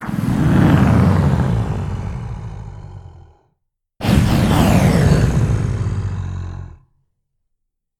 Dragon Roar
Category 🐾 Animals
creature creep dinosaur dragon enemy evil game-design moan sound effect free sound royalty free Animals